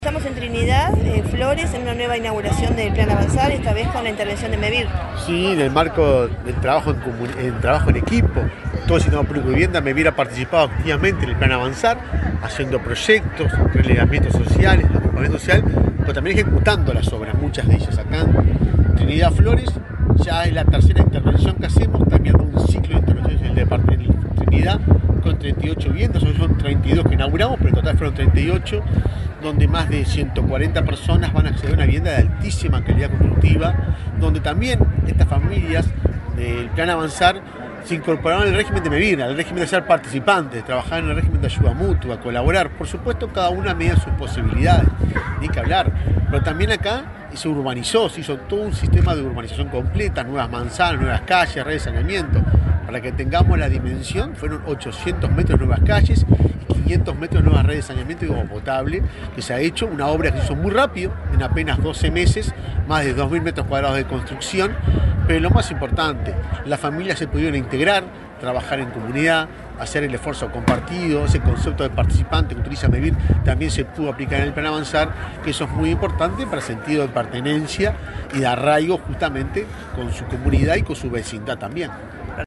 Declaraciones del presidente de Mevir, Juan Pablo Delgado
Declaraciones del presidente de Mevir, Juan Pablo Delgado 28/11/2024 Compartir Facebook X Copiar enlace WhatsApp LinkedIn El presidente de Mevir, Juan Pablo Delgado, dialogó con Comunicación Presidencial, antes de participar en la inauguración de viviendas del Plan Avanzar, en Trinidad, departamento de Flores.